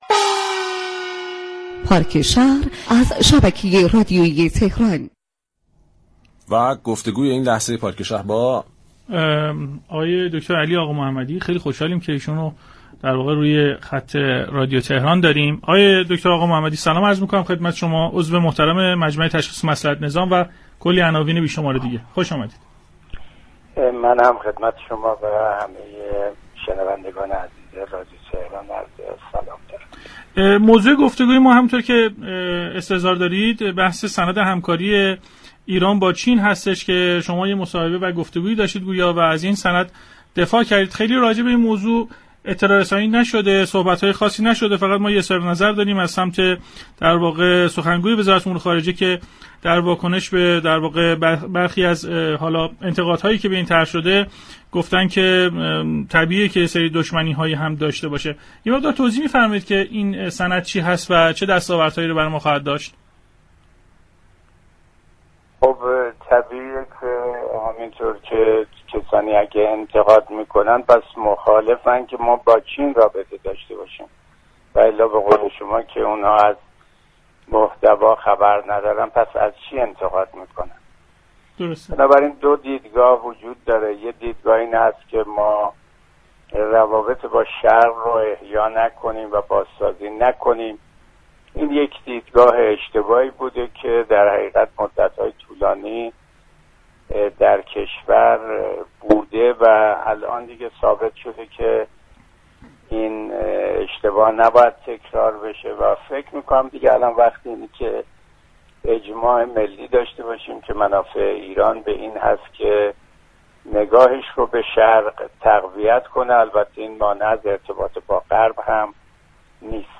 علی آقامحمدی در گفتگو با پارك شهر رادیو تهران اظهار داشت: برای نخستین بار در تاریخ ایران 17 شركت داخلی نفتی كشورمان برای دست‌یابی به افزایش تولید نفتی در حال انعقاد قراردادی برای تولید یك میلیون بشكه‌ی نفت در روز هستند.